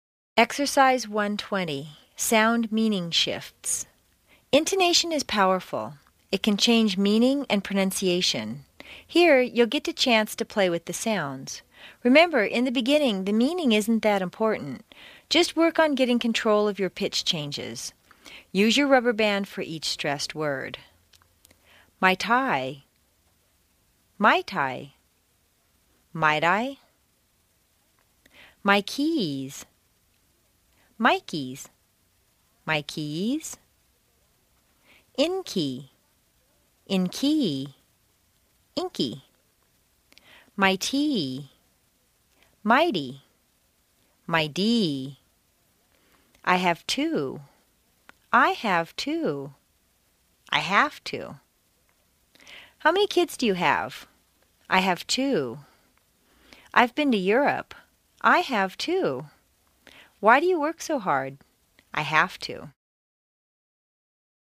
在线英语听力室美式英语正音训练第21期:Exercise 1-20 Sound/Meaning Shifts的听力文件下载,详细解析美式语音语调，讲解美式发音的阶梯性语调训练方法，全方位了解美式发音的技巧与方法，练就一口纯正的美式发音！